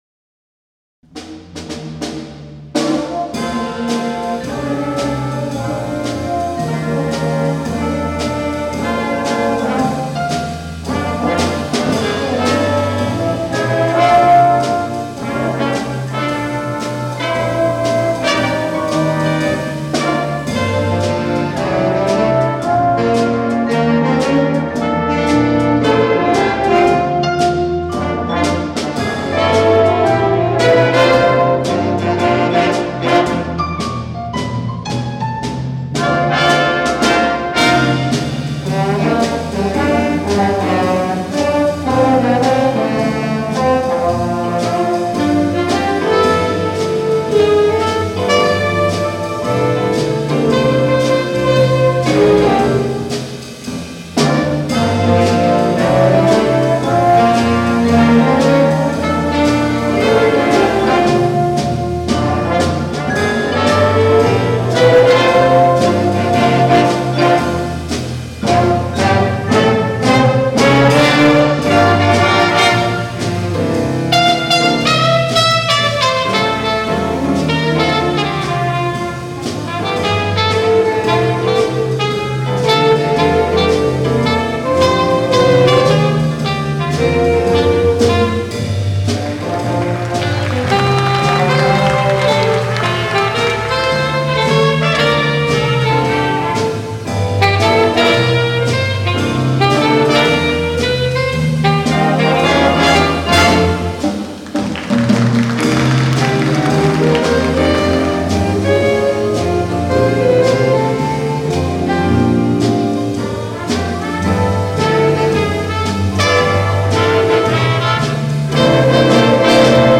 Jazz Concert 2023 Recordings